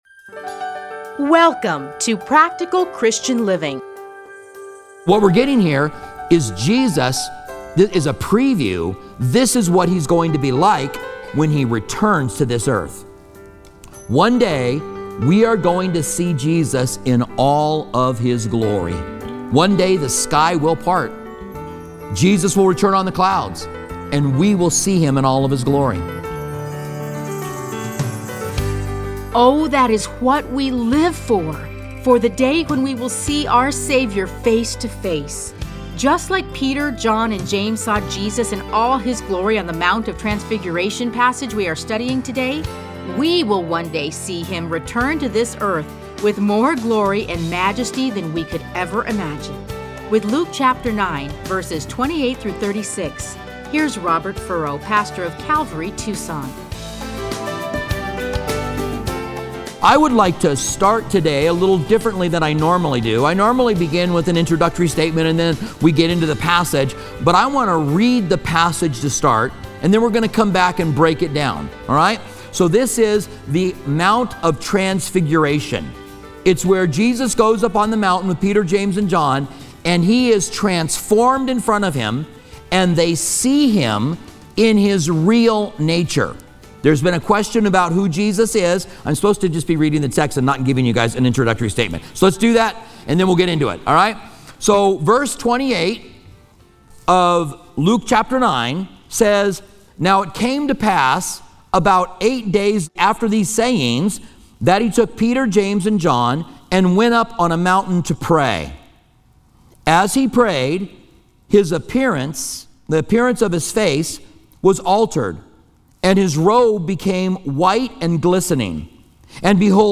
Listen to a teaching from Luke 9:28-36.